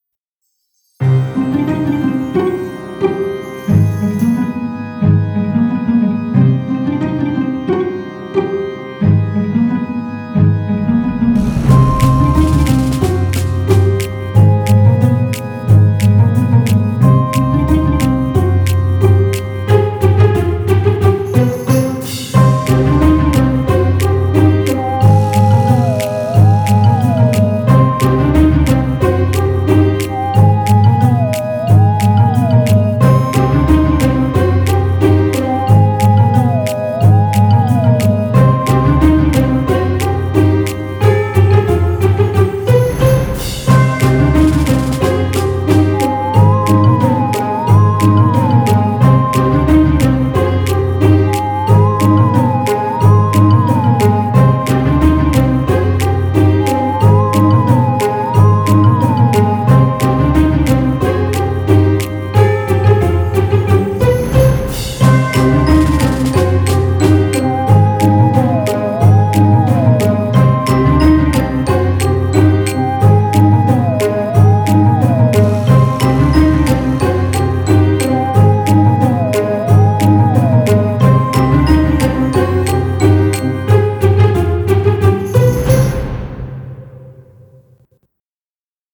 halloween_dance-music-generic.mp3